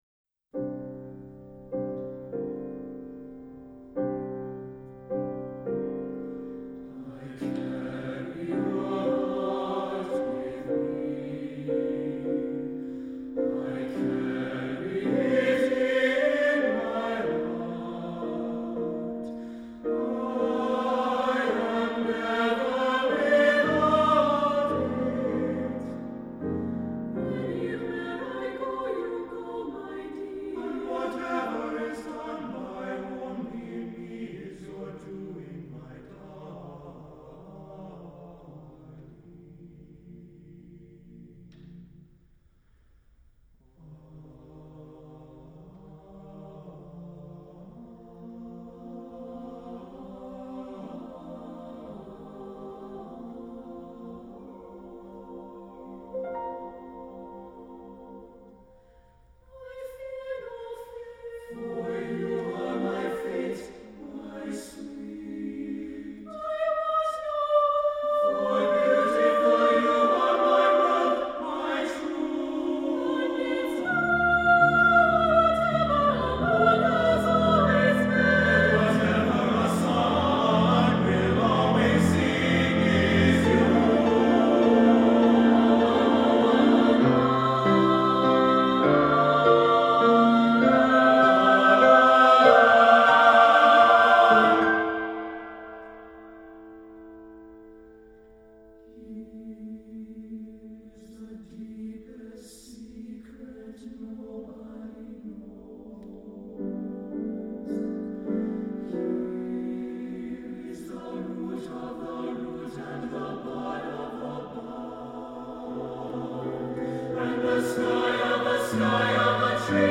for SATB Chorus and Piano (1993)
The musical emphasis is on the vocal lines.